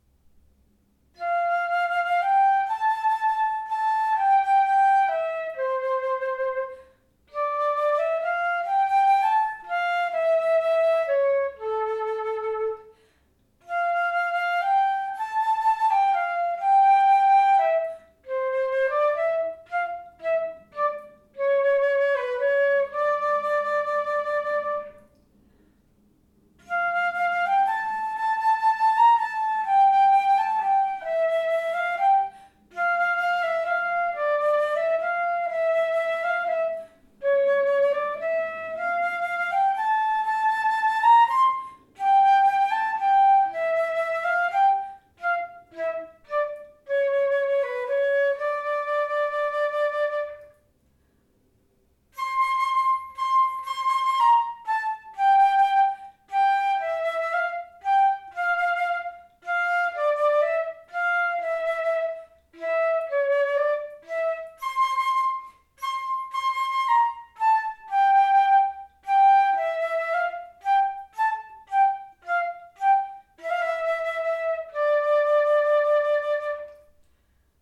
The Powell flute is a silver instrument with open-hole keys and a C foot.
As such, all the below recordings were made using the same recorder settings and player positioning (including microphone distance) within the same room.
Lyrical:  Powell flute (1928)
The fullness of the Powell enabled me to render the lyrical materials with flow and ease.
greensleeves_lyrical_powell.mp3